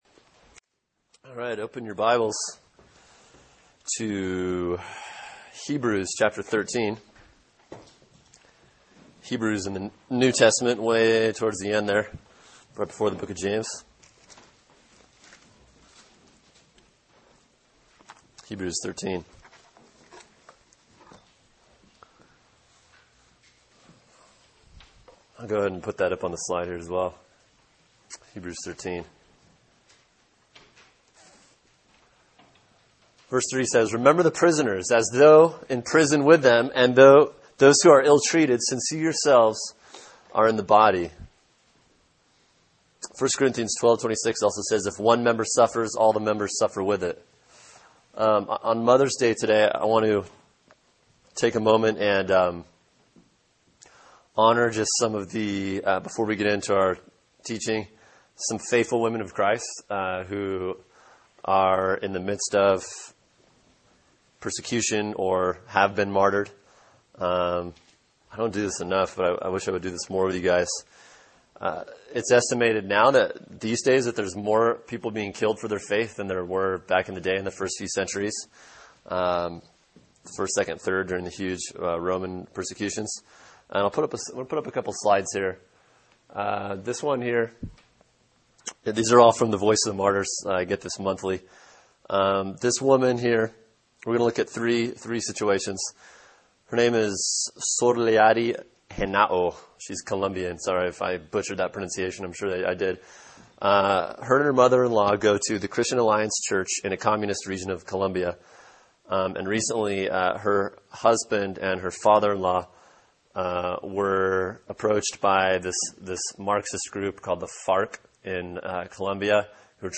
[sermon] various scriptures “God’s Call For Women” | Cornerstone Church - Jackson Hole